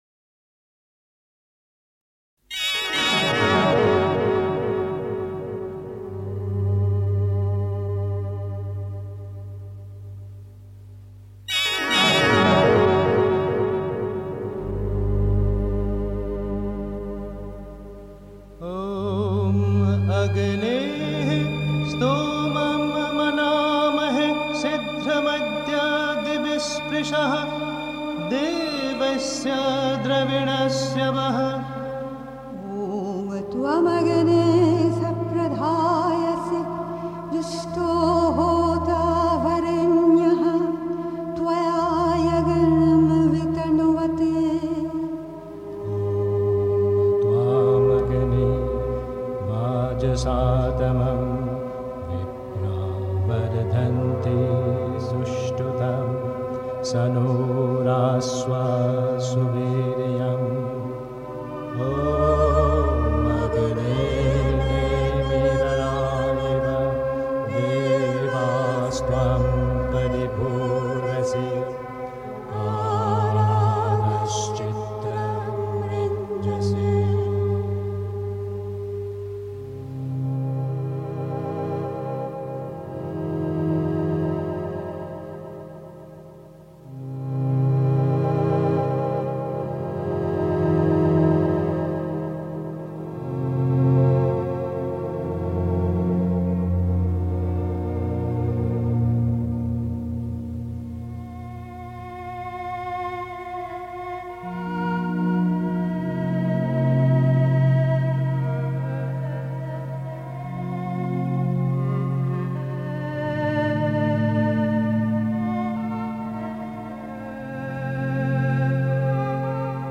Pondicherry. 2. Unwissenheit ist eine verbreitete Krankheit (Die Mutter, White Roses, 09 February 1956) 3. Zwölf Minuten Stille.